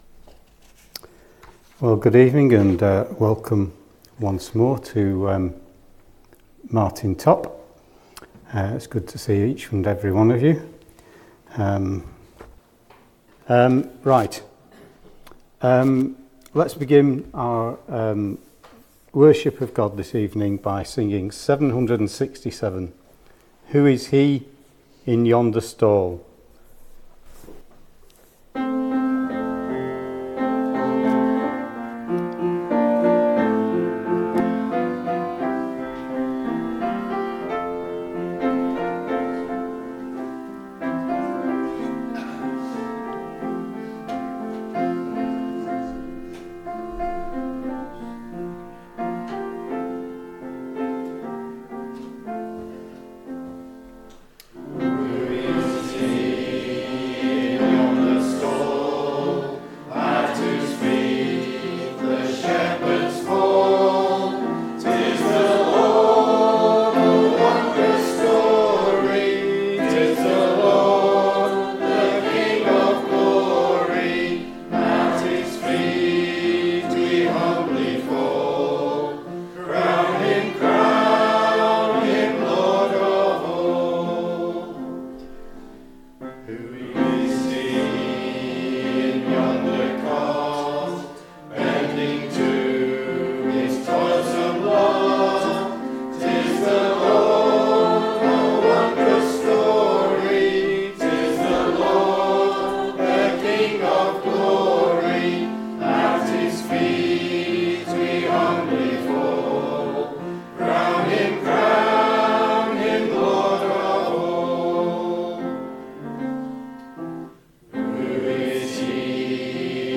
Below is audio of the full service.
2025-12-28 Evening Worship If you listen to the whole service on here (as opposed to just the sermon), would you let us know?